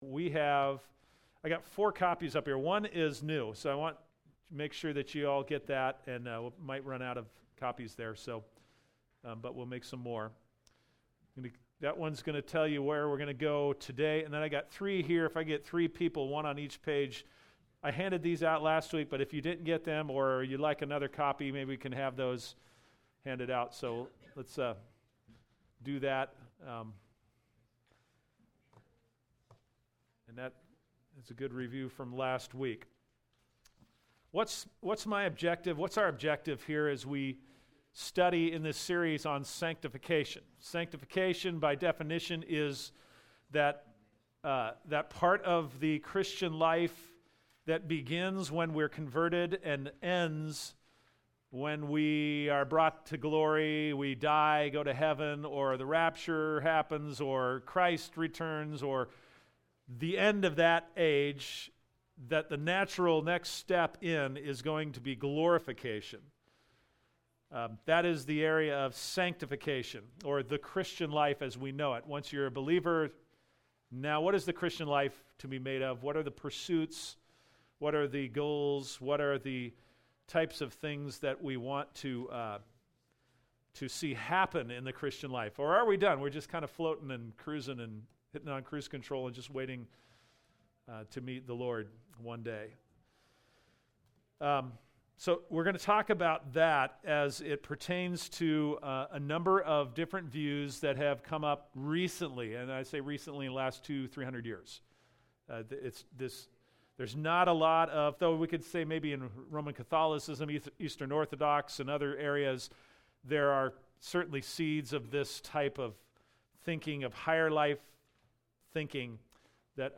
Select Scriptures (Sunday School) – Sanctification and the Christian life (part 2)